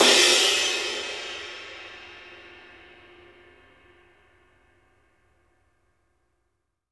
DARKCRASH -L.wav